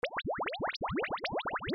burbujas
Sonido FX 11 de 42
burbujas.mp3